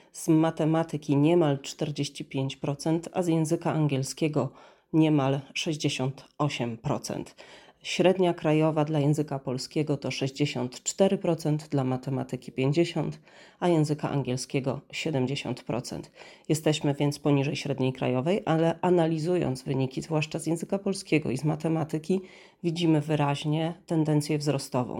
O tym mówi rzeczniczka